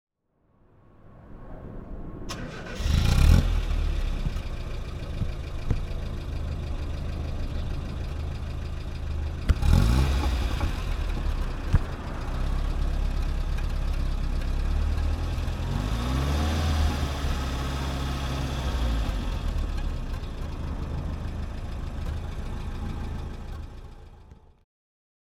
Mazda 929 Coupé (1975) - Starten und Leerlauf